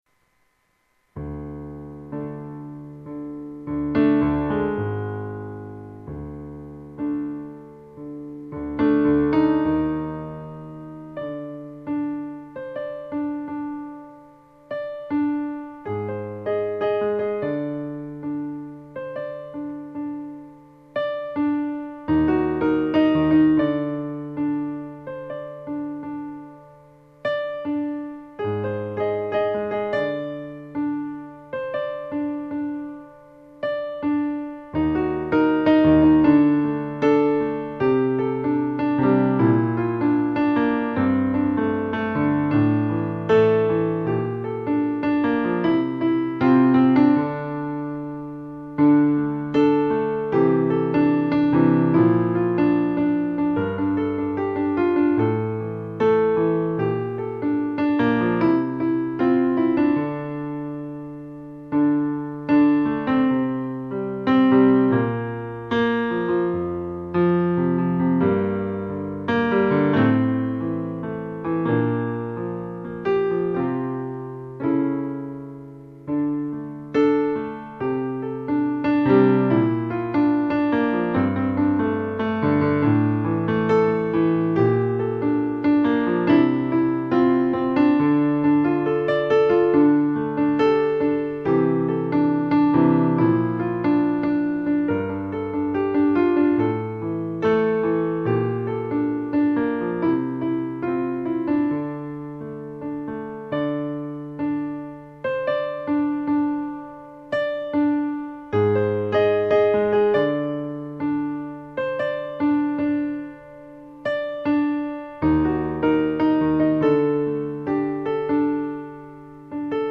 Pretty melody, though.